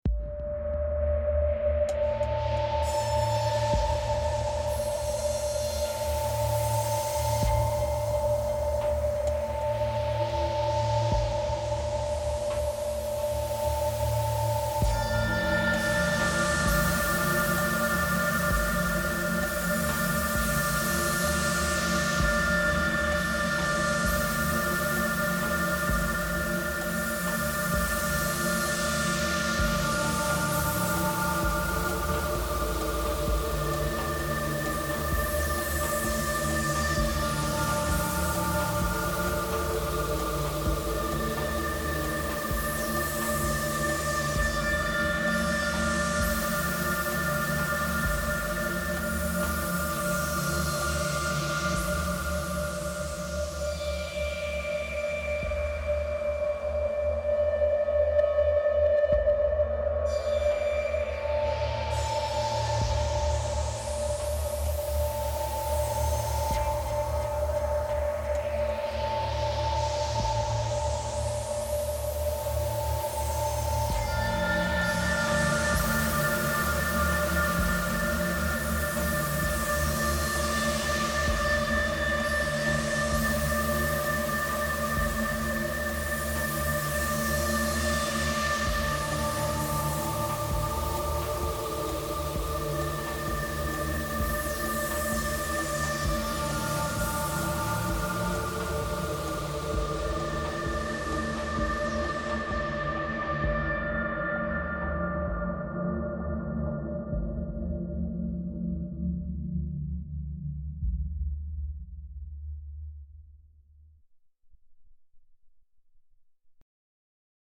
Claude made this
and lush FX.
Sine table + triangle oscillators with subtle detuning.
Long attack (80) and release (90) for drone character.
Chorus for stereo width, valve drive at 10 for warmth.
Digital vocal 3 + random collection waveforms for texture.
Osc2 tuned +12 semitones for octave shimmer.
Bandpass 6/6 filter — ethereal, not boomy.
Ring mod at 20 for metallic harmonics.
6-slot mod matrix with alt-waveform LFO for organic movement.